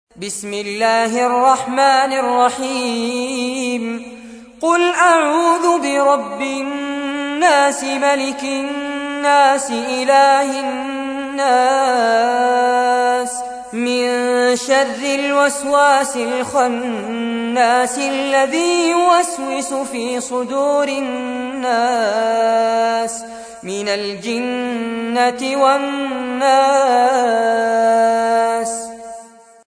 تحميل : 114. سورة الناس / القارئ فارس عباد / القرآن الكريم / موقع يا حسين